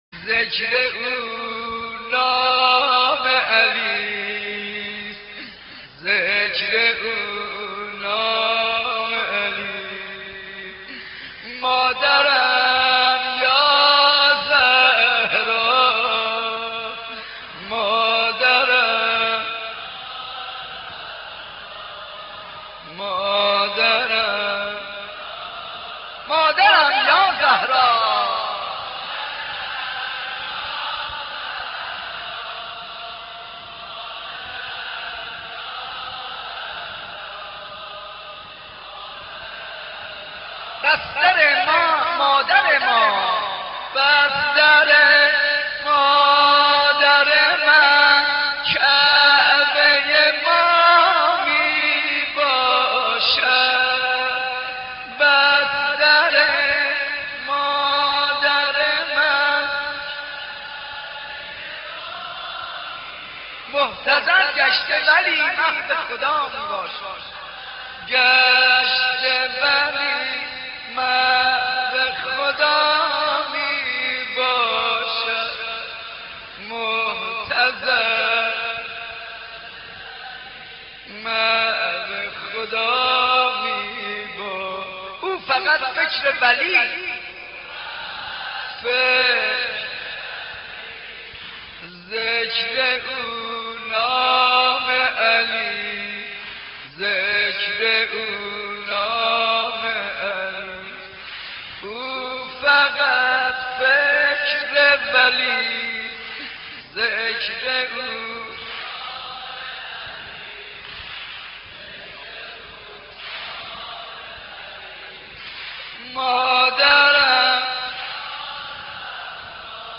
دانلود مداحی بستر مادر من کعبه ی ما می باشد - دانلود ریمیکس و آهنگ جدید
مداحی حاج منصور ارضی به مناسبت ایام شهادت حضرت فاطمه(س) (13:30)